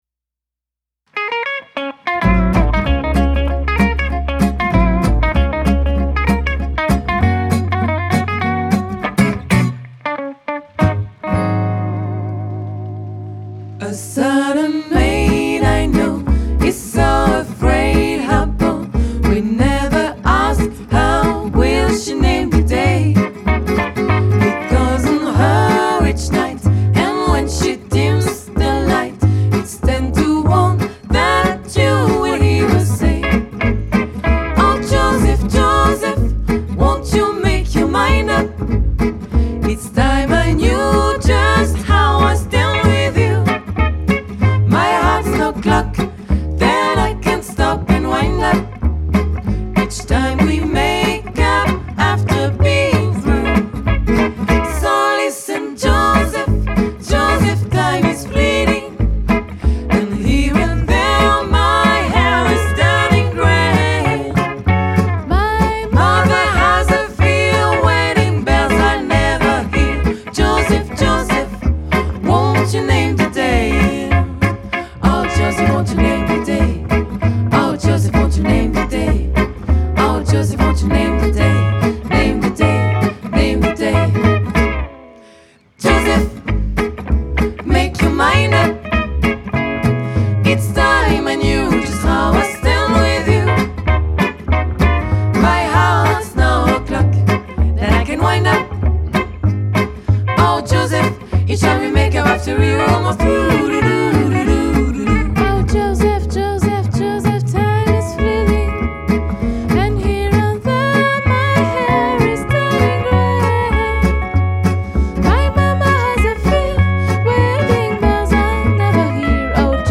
guitare rythmique
guitare solo
contrebasse
batterie